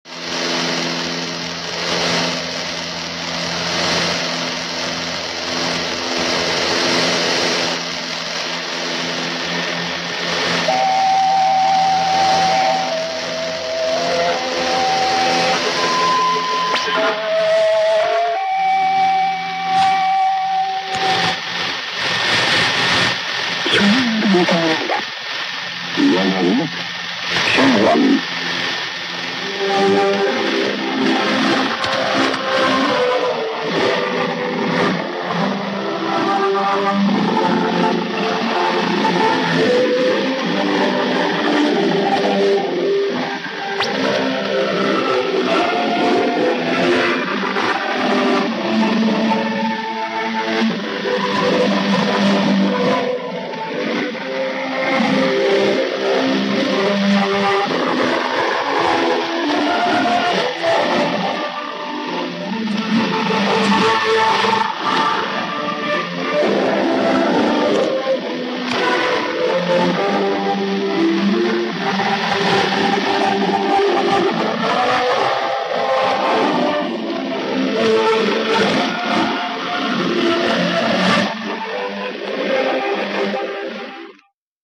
It's a transmitter ID used by the Russian-language broadcast service of the Democratic People's Republic of Korea, a land where 1953 never ended. Thrill to ominous 50-Hz hum, phase shifted interval signals, Brave People's Sign-On, and finally an appropriately distorted national anthem. Retro-cool audio clipping is all theirs, at no extra charge to Glorious Worker.